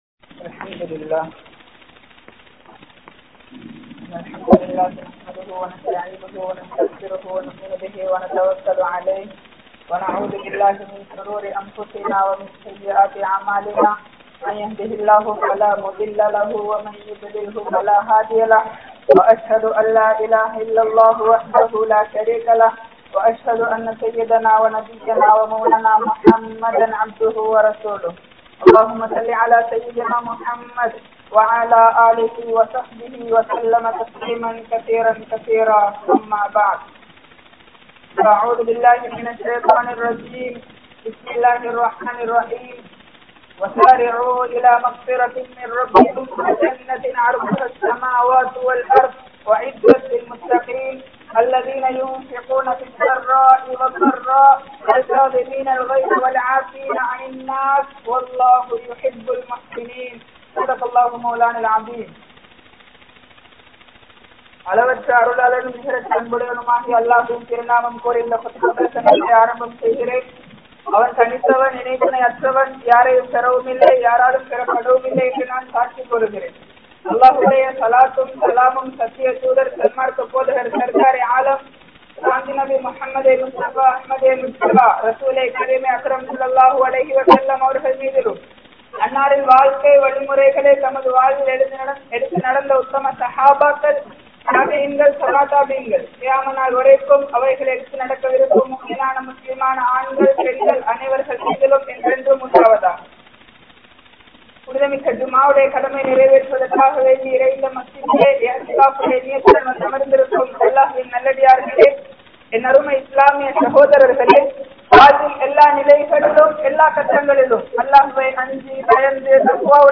Koafaththin Vilaivuhal (கோபத்தின் விளைவுகள்) | Audio Bayans | All Ceylon Muslim Youth Community | Addalaichenai
Wellampitiya, Polwatte, Masjidun Noor Jumua Masjidh